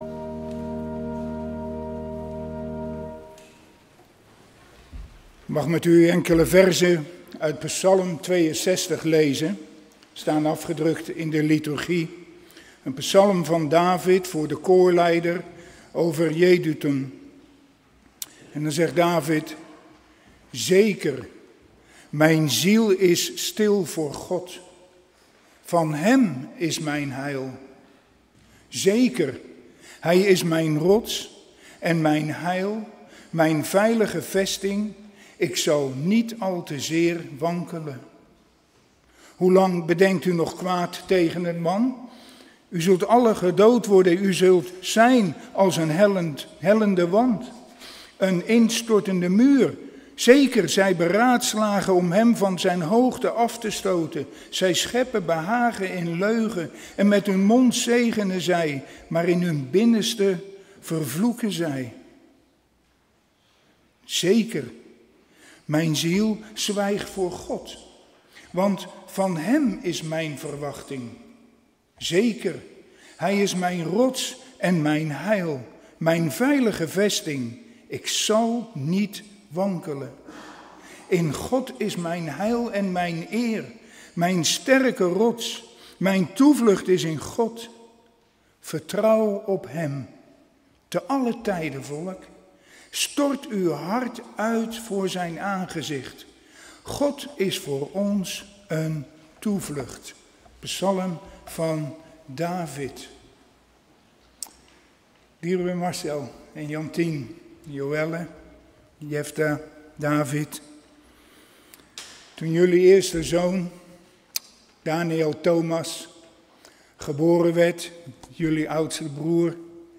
In de preek aangehaalde bijbelteksten (Statenvertaling)Psalmen 62:1-81 Een psalm van David, voor den opperzangmeester, over Jeduthun. (62:2) Immers is mijn ziel stil tot God; van Hem is mijn heil. 2 (62:3) Immers is Hij mijn Rotssteen en mijn Heil, mijn Hoog Vertrek, ik zal niet grotelijks wankelen. 3 (62:4) Hoe lang zult gijlieden kwaad aanstichten tegen een man?